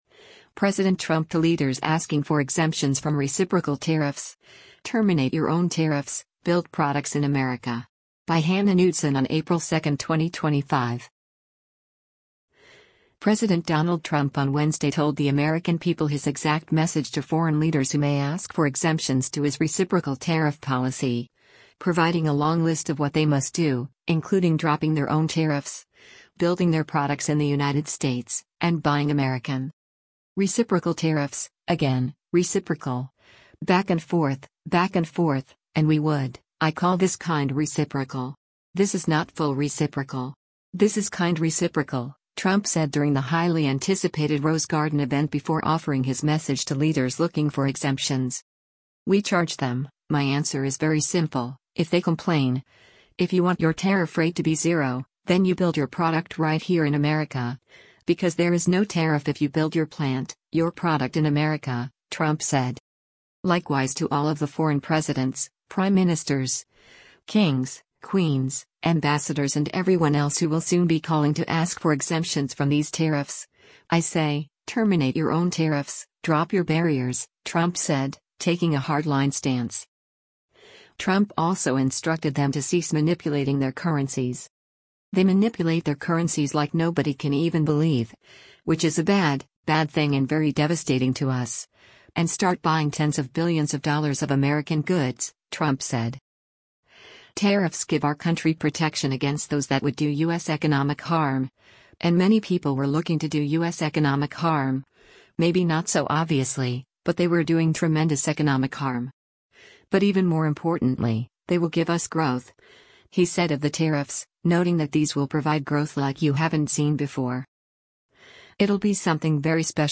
US President Donald Trump during a tariff announcement in the Rose Garden of the White Hou